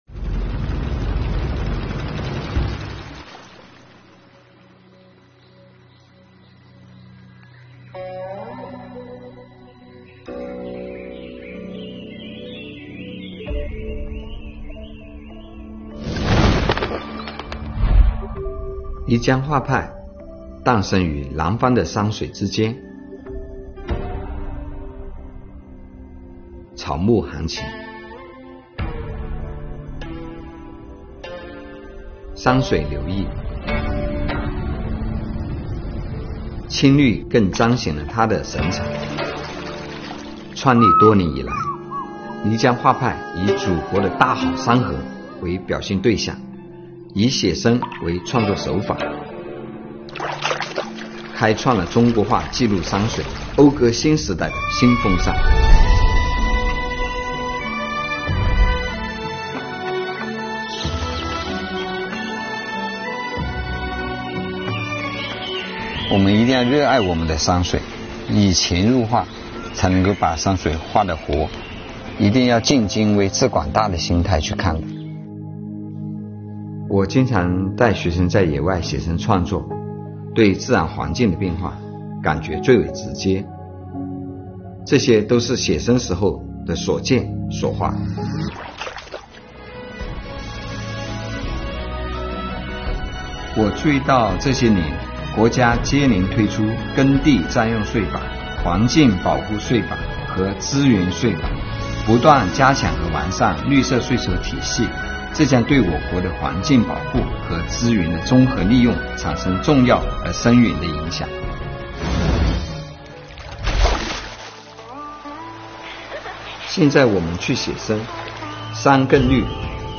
广西税务年度公益广告大片：《留此青绿，共向未来》